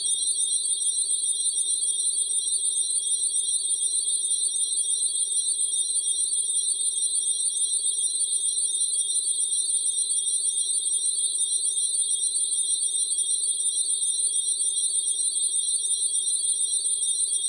electric-cicadas01-
ambient bed bell chimes cicadas digital ding effect sound effect free sound royalty free Sound Effects